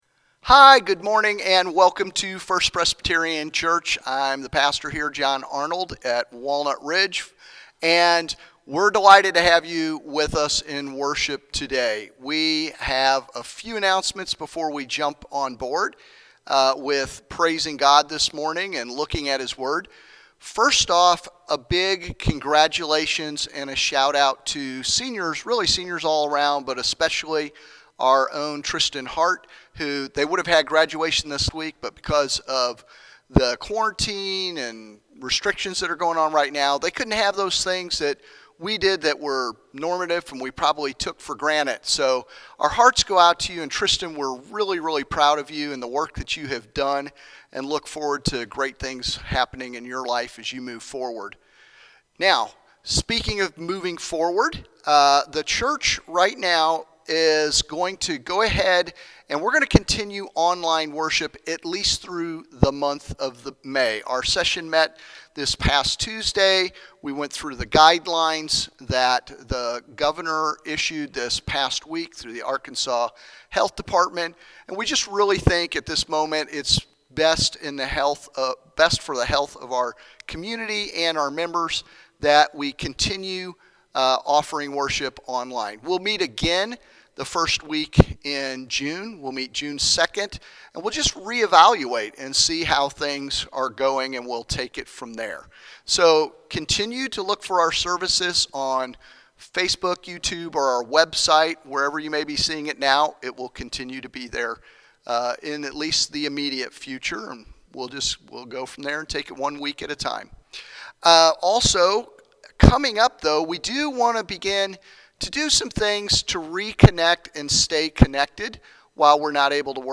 may-10_worship.mp3